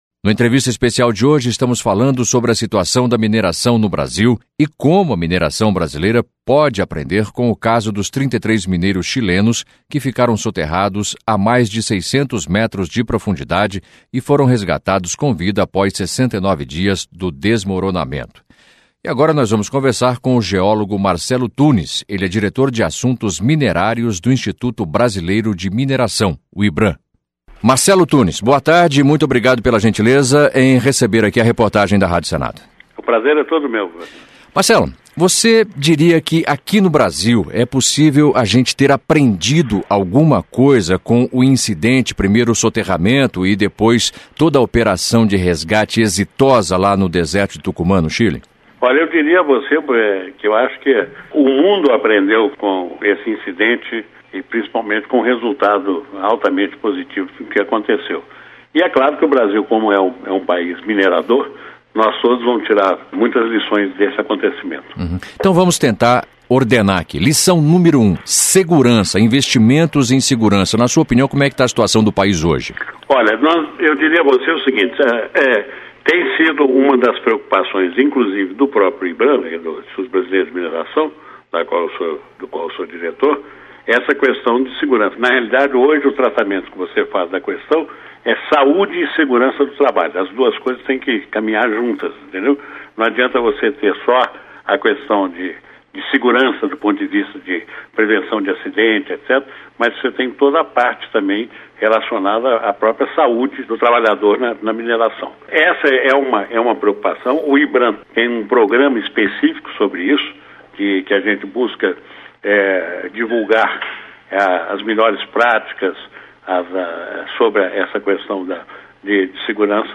Entrevista com o senador Delcídio Amaral